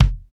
KIK XC.BDR01.wav